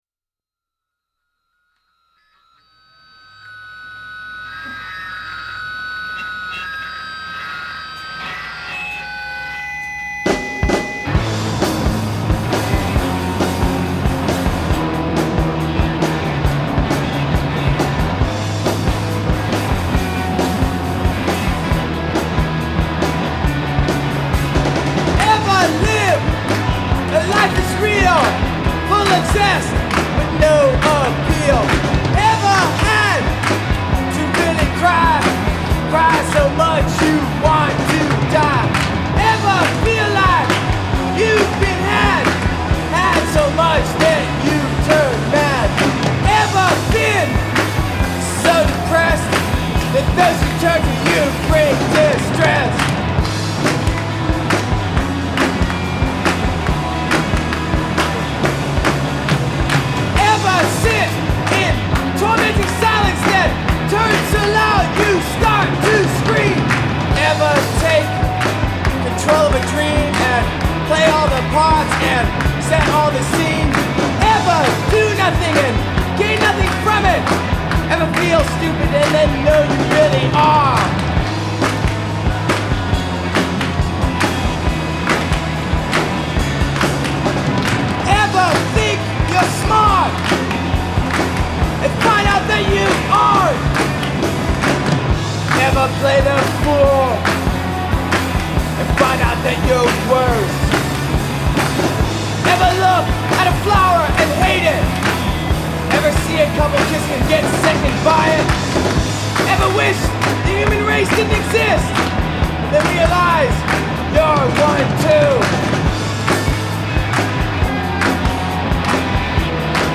They didn't sound like either meatheads or goofballs.